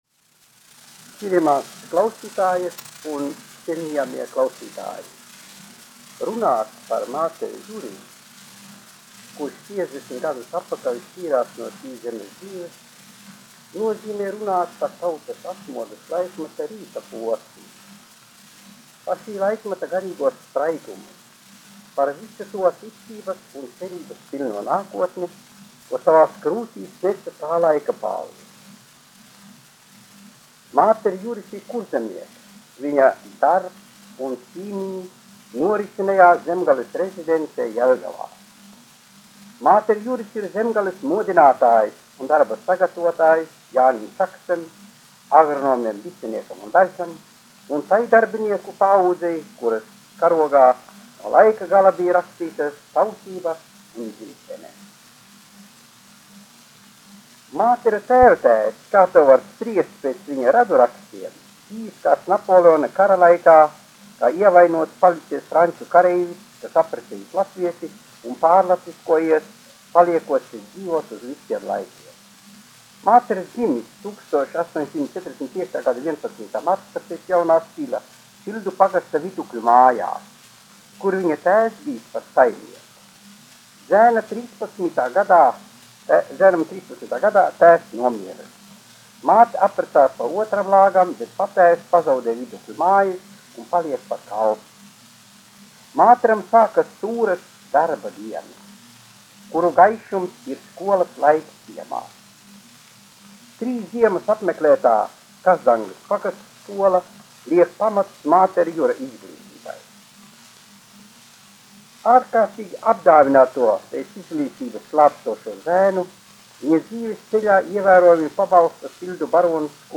1 skpl. : analogs, 78 apgr/min, mono ; 25 cm
Latviešu runas, uzrunas utt -- Vēsture un kritika
Skaņuplate